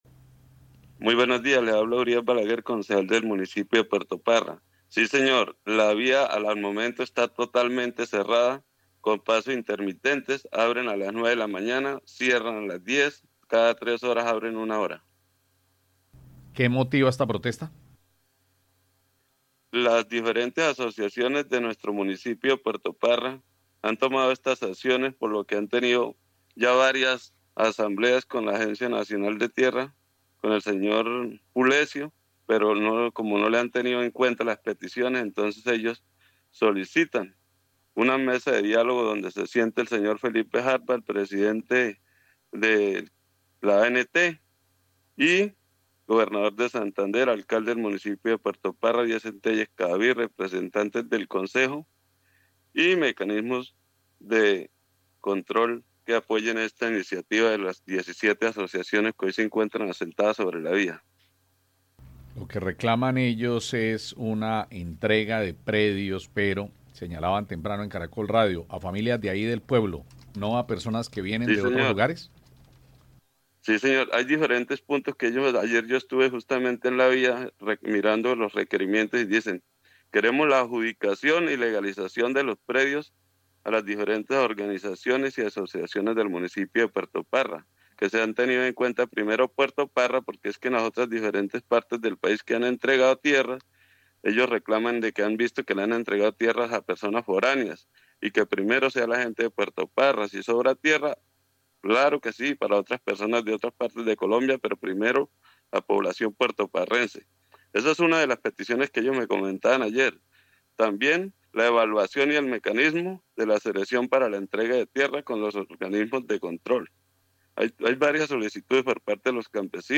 Urias Balaguer Marín, concejal del municipio de Puerto Parra